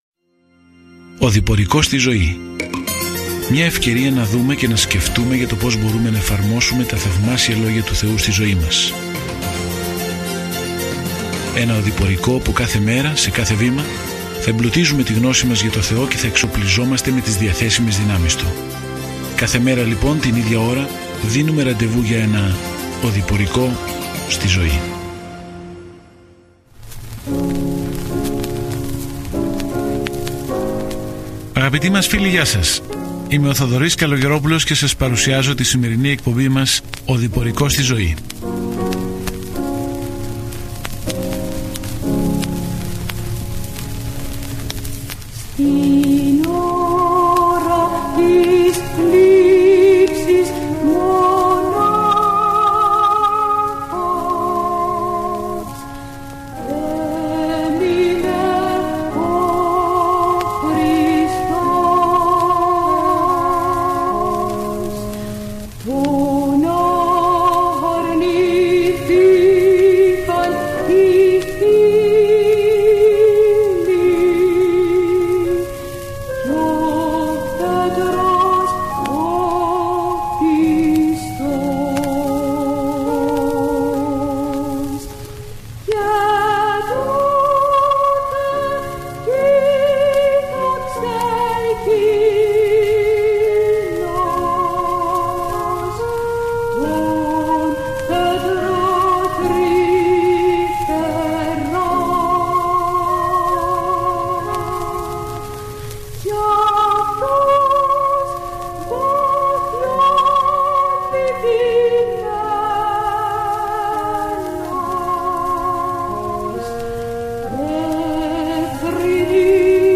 Κείμενο ΠΡΟΣ ΡΩΜΑΙΟΥΣ 1:1-9 Ημέρα 1 Έναρξη αυτού του σχεδίου Ημέρα 3 Σχετικά με αυτό το σχέδιο Η επιστολή προς τους Ρωμαίους απαντά στην ερώτηση «ποια είναι τα καλά νέα;» Και πώς μπορεί κάποιος να πιστέψει, να σωθεί, να λυθεί από τον θάνατο και να αναπτυχθεί στην πίστη. Καθημερινά ταξιδεύετε στους Ρωμαίους καθώς ακούτε την ηχητική μελέτη και διαβάζετε επιλεγμένους στίχους από τον λόγο του Θεού.